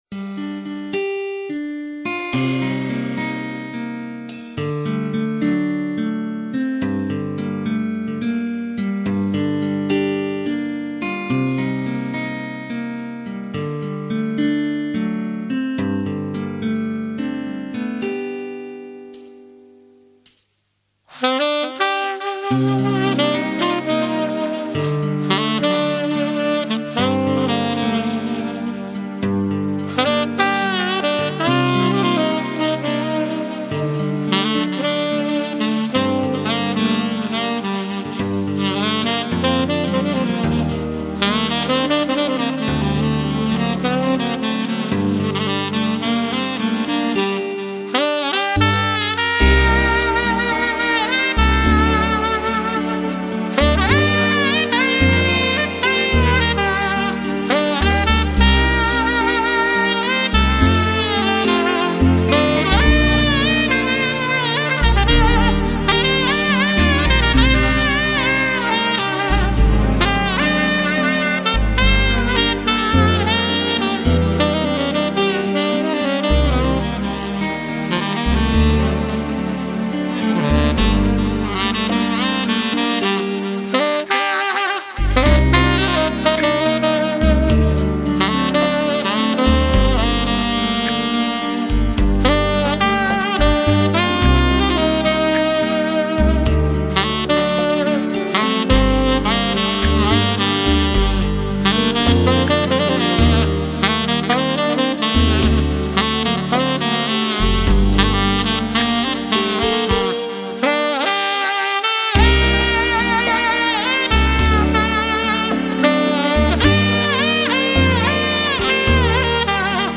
Жанр: Instrumental Pop